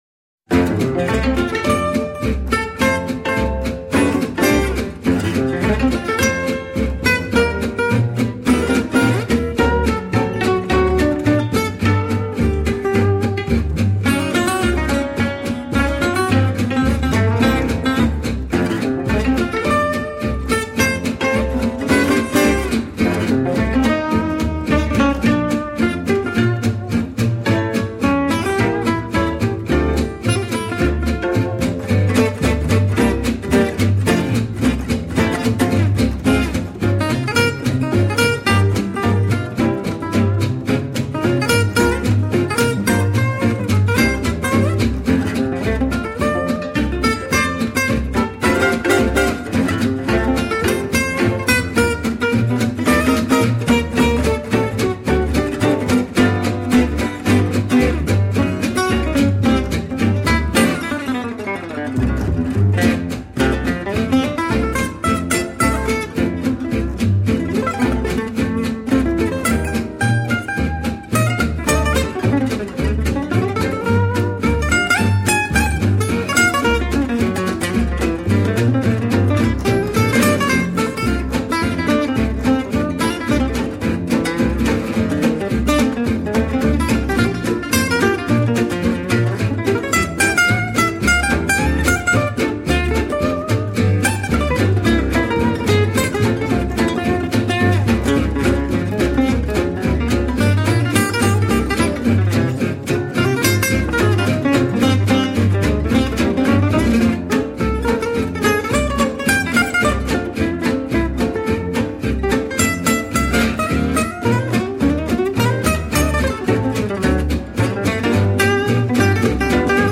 CD, gypsy swing